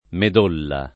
midolla [mid1lla] s. f. — ant. medolla [